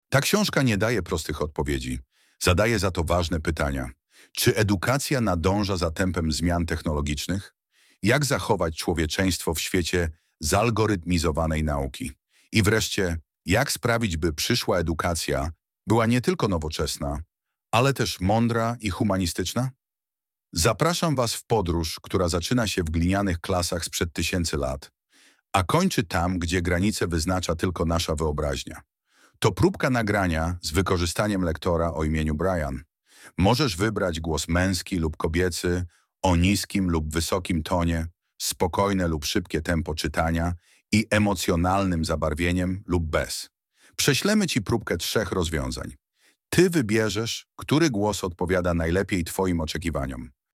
Nagrywanie audiobooków z wykorzystaniem inteligentnego oprogramowania to nowoczesny sposób tworzenia nagrań głosowych, w którym cała narracja powstaje bez udziału tradycyjnego lektora, za to z użyciem sztucznie wygenerowanego głosu o wysokiej jakości.